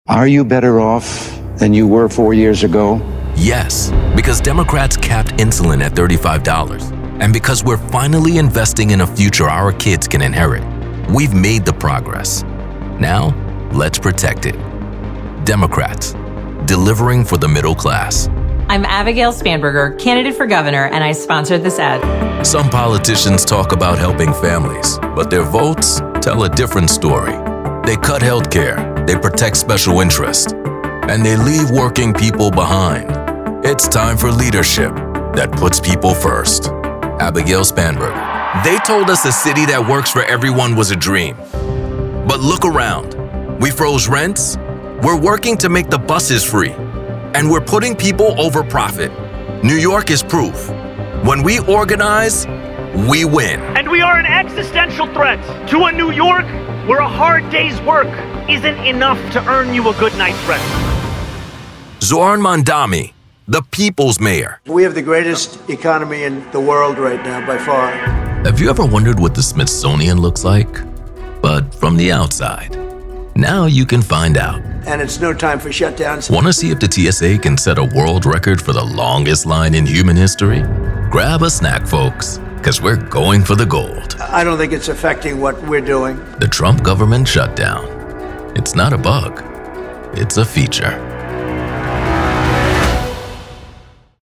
mALE Democrat Voice Actors
Male Democratic Voices